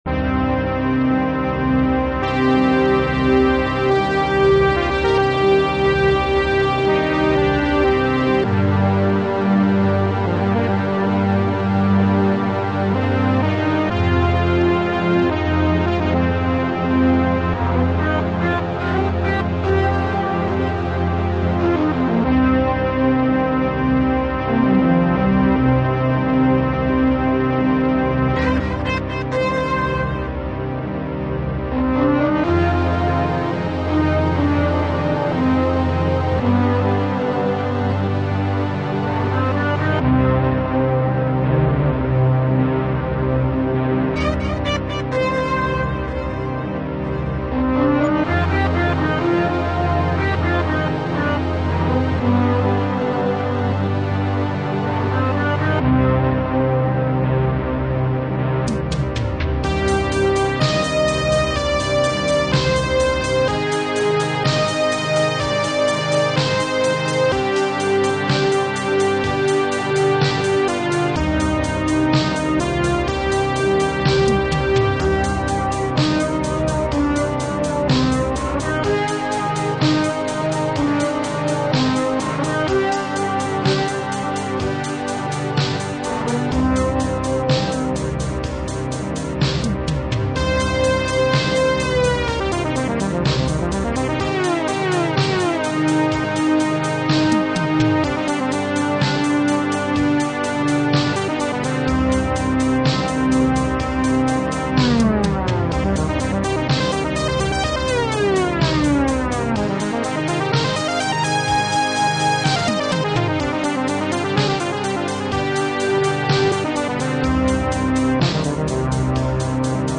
Digital title music
Features digitized title soundtrack at 12.3KHz